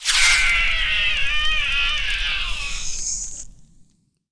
Wep Fireball Playerhit Sound Effect
wep-fireball-playerhit.mp3